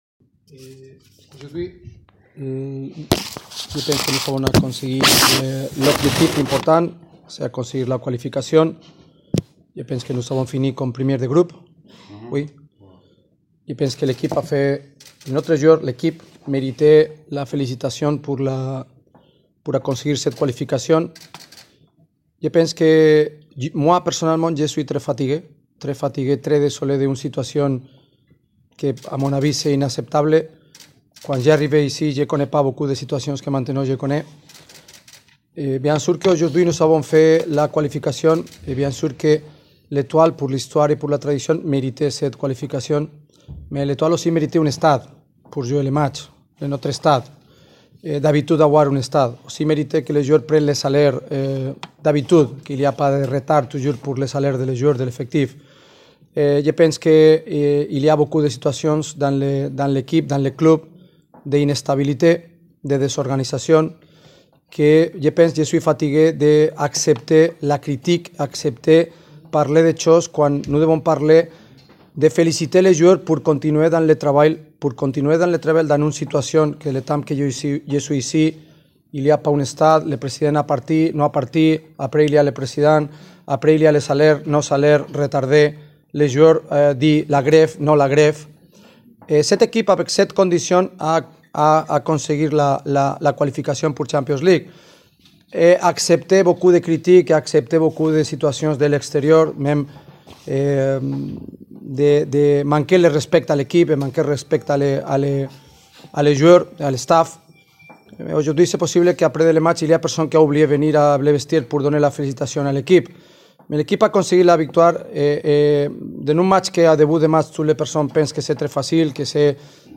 قال خوان كارلوس جاريدو خلال المؤتمر الصحفي بعد نهاية مباراة النجم الساحلي و بلاتنيوم من الزمبابوي : “لقد حققنا اليوم الهدف وتأهلنا إلى ربع النهائي في صدارة مجموعتنا لكن رغم هذا الإنجاز فهناك من لم يأت لحجرات الملابس لتقديم التهاني للاعبين وللجهاز الفني”.
تصريح خوان كارلوس جاريدو